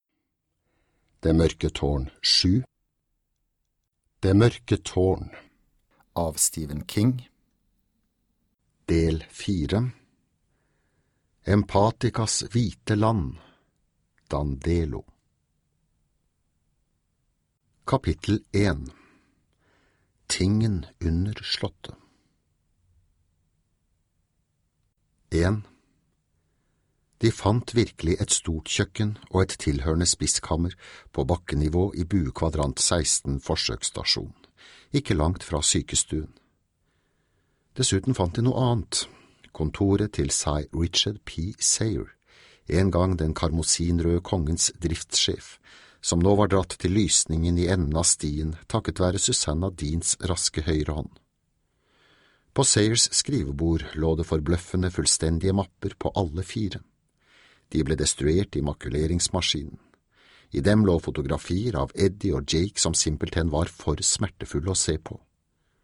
Det mørke tårn VII - Del 4 - Empathicas hvite land Dandelo (lydbok) av Stephen King